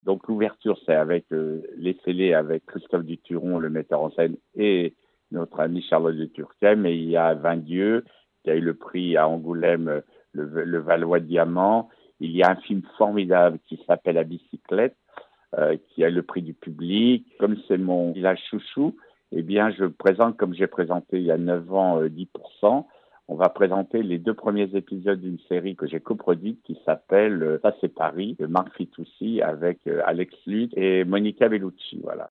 Dominique Besnehard était au micro d'ODS Radio.